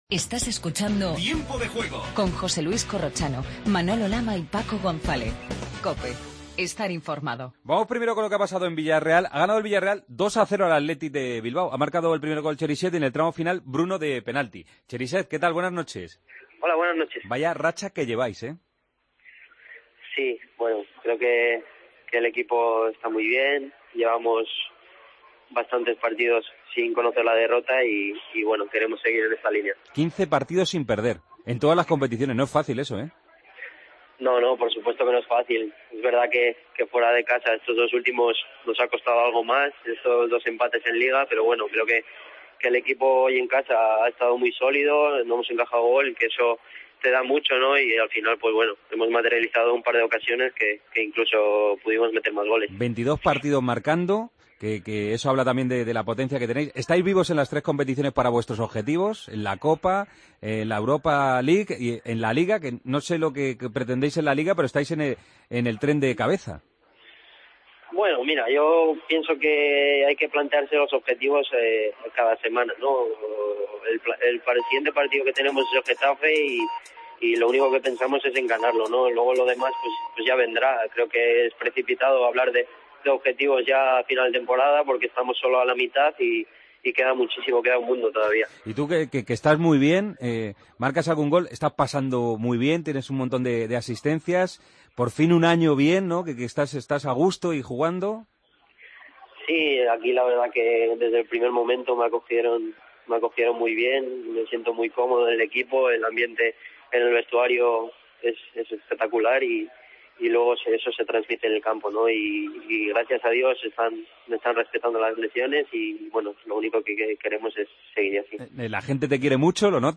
AUDIO: Último repaso a la jornada de liga. El Villarreal ha ganado sin problemas al Athletic. Entrevista a Cheryshev.
Entrevista a Manucho.